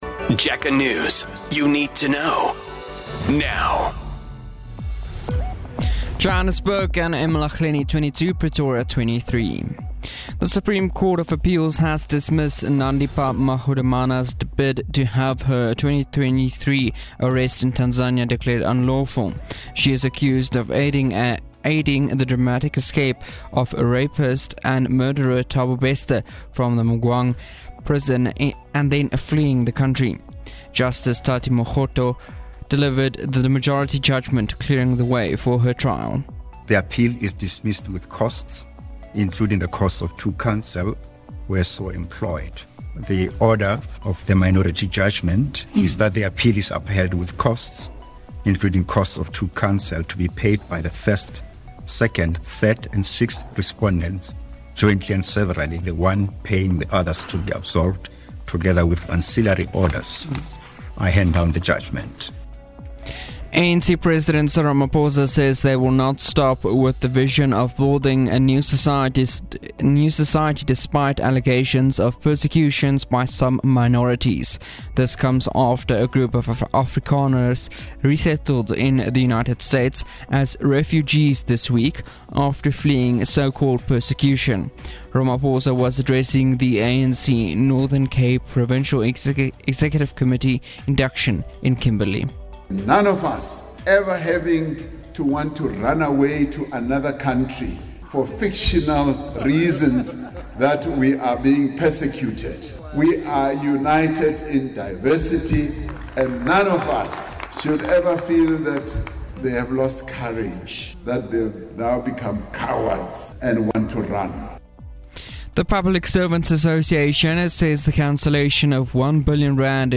Jacaranda FM News Bulletins